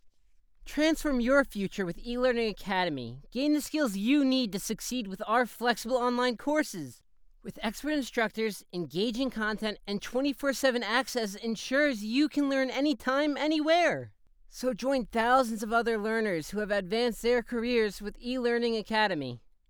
high tone. medium tone, new yorker,
Ranging from mid-high pitched tones, neardy, and nasally.
Microphone Rode NT1